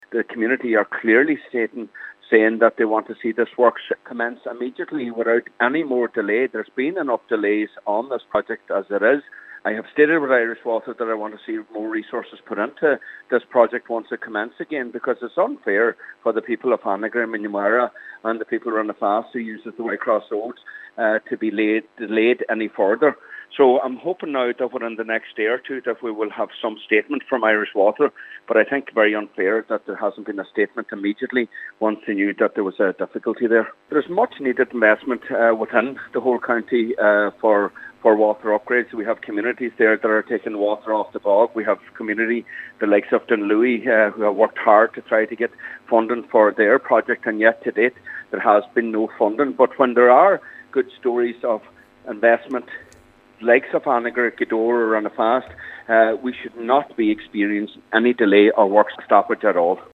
Councillor Micheal Cholm MacGiolla Easbuig is urging Irish Water to ensure works on the new water line get up and running again as soon as possible: